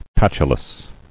[pat·u·lous]